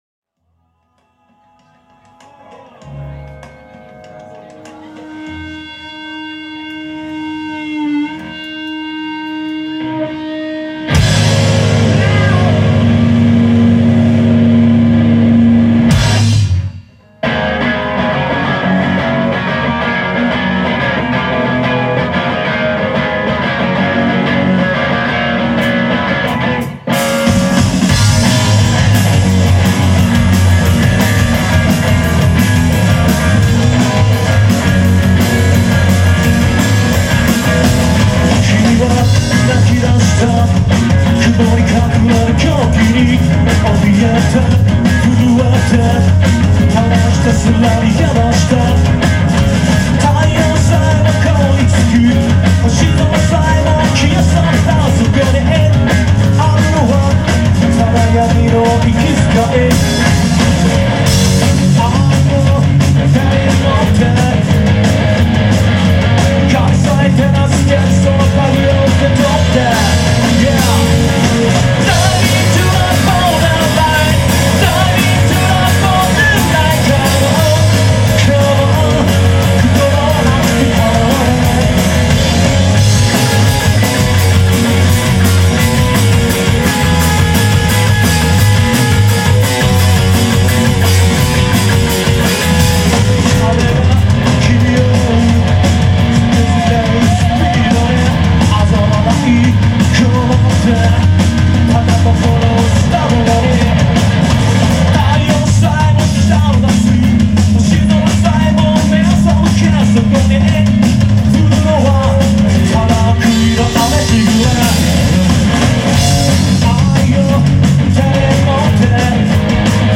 ライヴサーキット方式、下北沢にてcco、440、251で行われるミュージックフェス。
30分、サウンドチェックほぼ無し一本勝負。